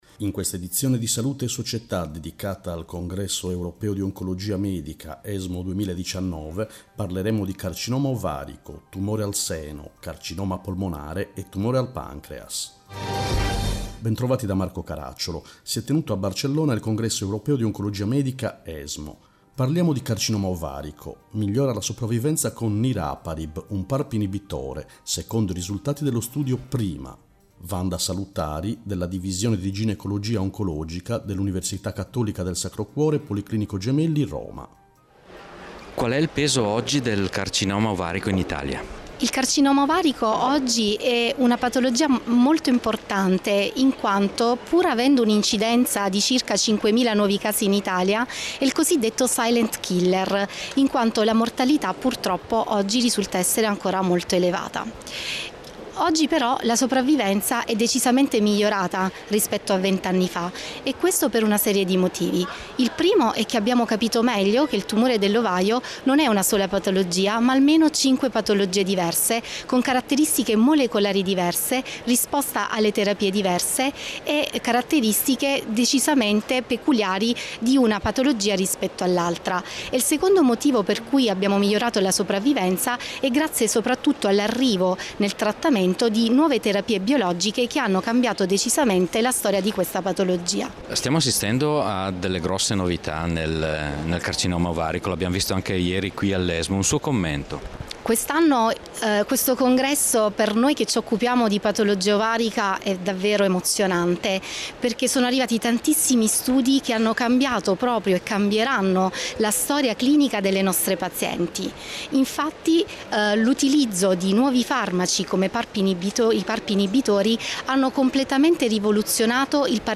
In questa edizione: Esmo 2019, Carcinoma ovarico Esmo 2019, Tumore al seno Esmo 2019, Carcinoma polmonare Esmo 2019, Tumore al pancreas Interviste